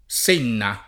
vai all'elenco alfabetico delle voci ingrandisci il carattere 100% rimpicciolisci il carattere stampa invia tramite posta elettronica codividi su Facebook Senna [ S% nna ] top. f. (Tosc.) — un torrente dell’Amiata e minori corsi d’acqua della Tosc. meridionale